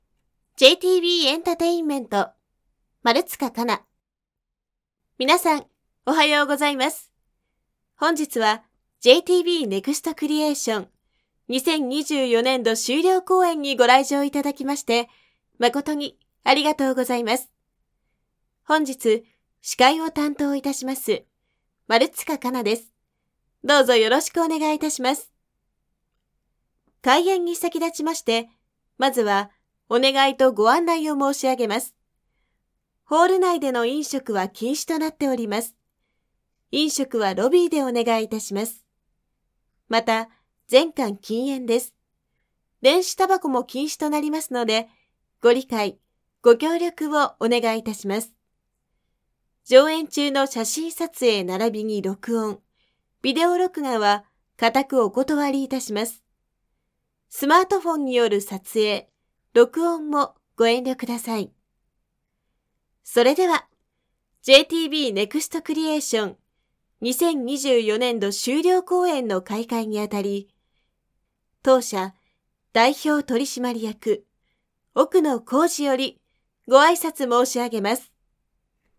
方　言　：　兵庫県
MCサンプル